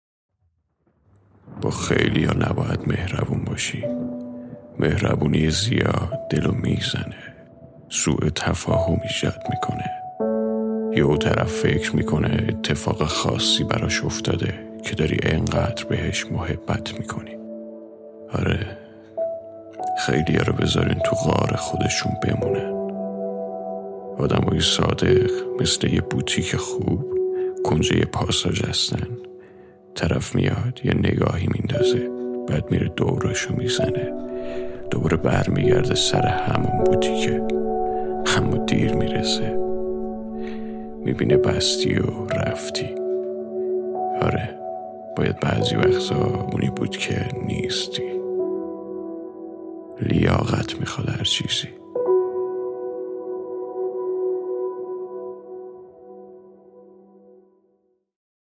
سوء تفاهم